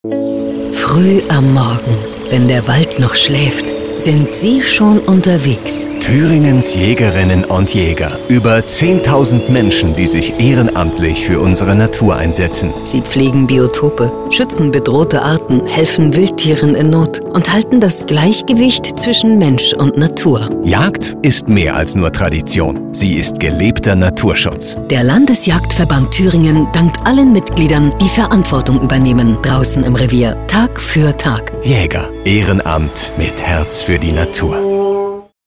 Dezember setzt der Landesjagdverband Thüringen e. V. (LJVT) ein starkes Zeichen: Vom 1. bis 7. Dezember schalten wir auf LandesWelle Thüringen und Antenne Thüringen einen landesweiten Radiospot, der die Bedeutung des ehrenamtlichen Engagements unserer Jägerinnen und Jäger in den Mittelpunkt stellt.
Hier ist unser Radio- Spot